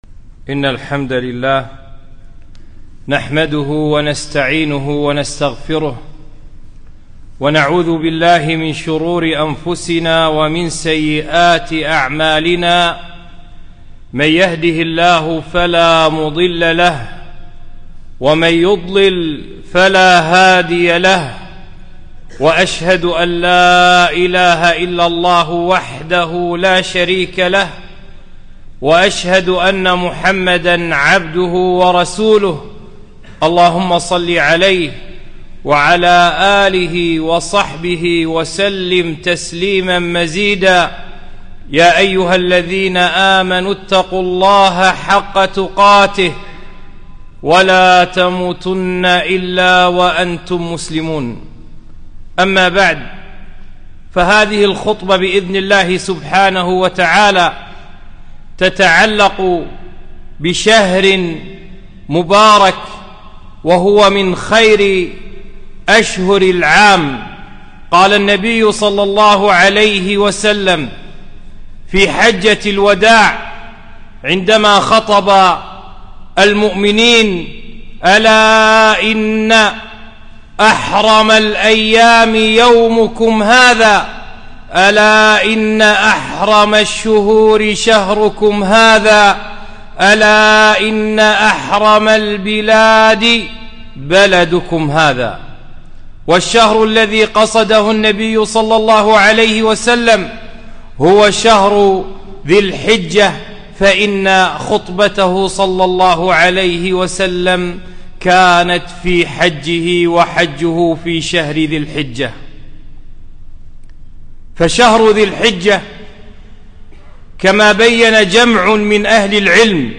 خطبة - شهر ذي الحجة فضائل وأحكام